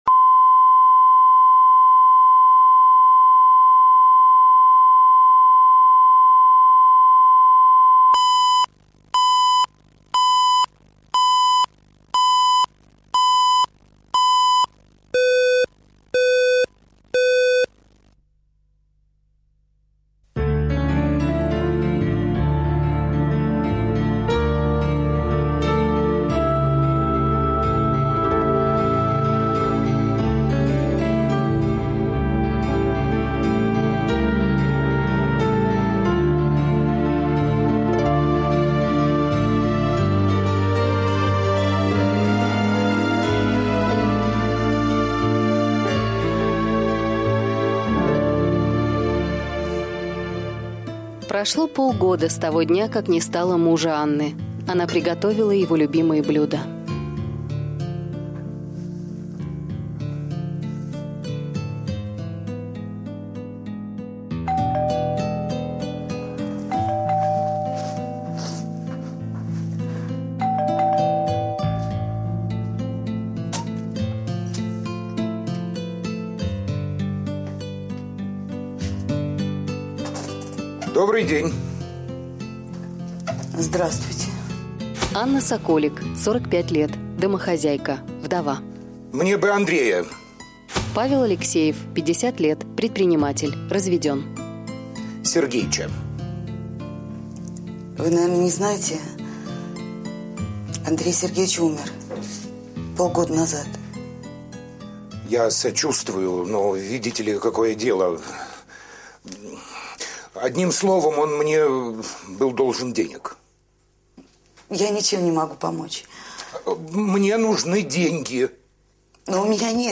Аудиокнига Требуется уборщица | Библиотека аудиокниг
Прослушать и бесплатно скачать фрагмент аудиокниги